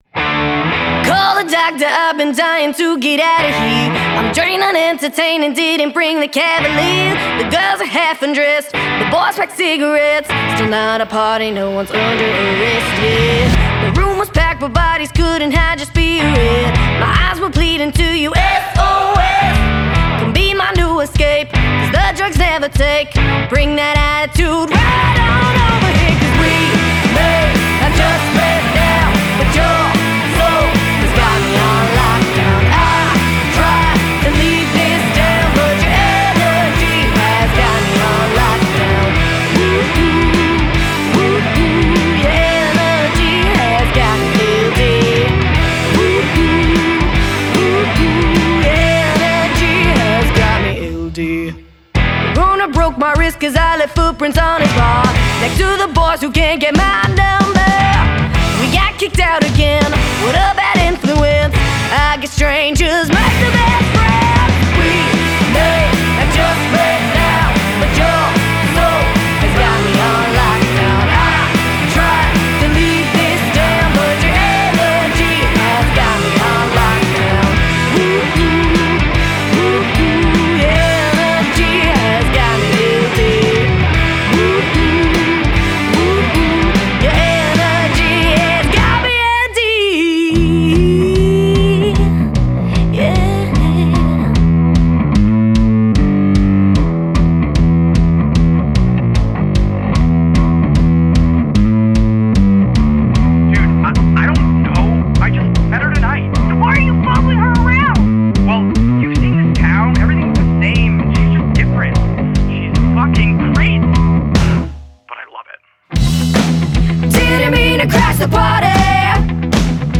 Rock
vocals
bass
keyboards, guitar
drums